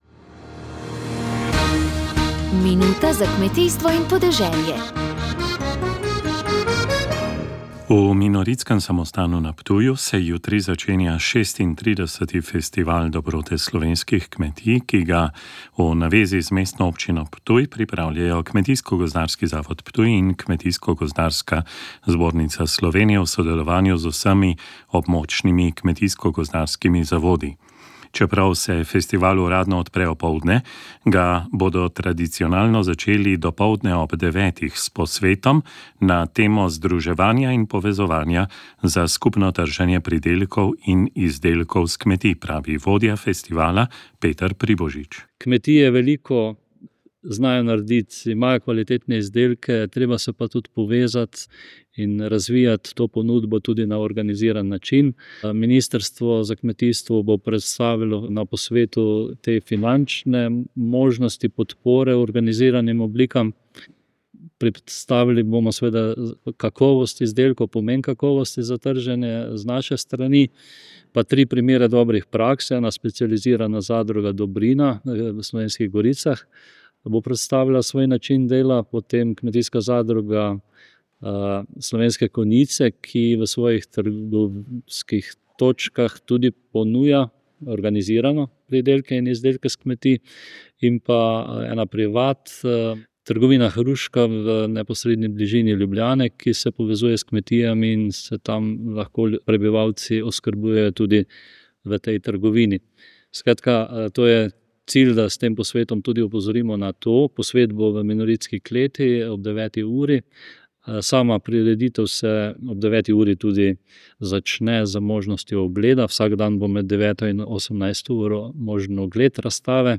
Oddaja je nastala na mini počitnicah Radia Ognjišče na Bledu.